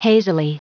Prononciation du mot hazily en anglais (fichier audio)
Prononciation du mot : hazily